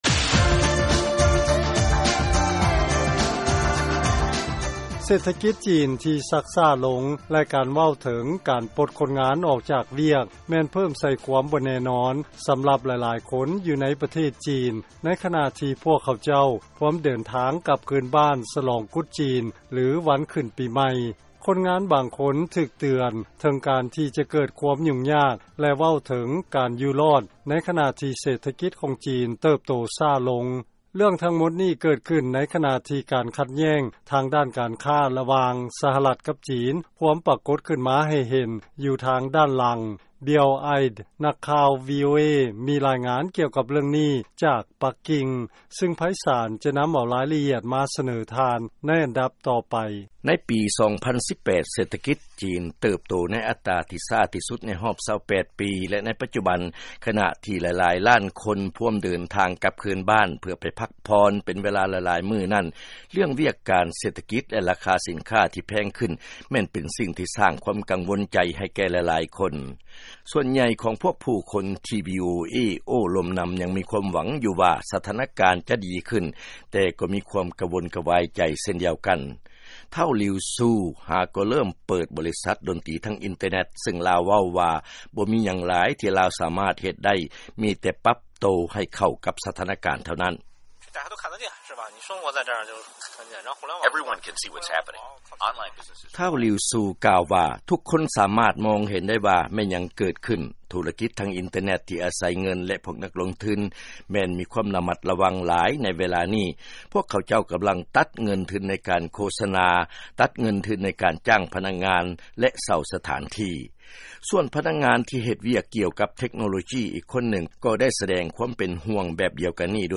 ເຊີນຟັງລາຍງານ ເສດຖະກິດ ທີ່ຊັກຊ້າລົງ ສ້າງຄວາມກັງວົນໃຈ ໃຫ້ແກ່ຜູ້ຄົນ ໃນໂອກາດ ວັນກຸດຈີນ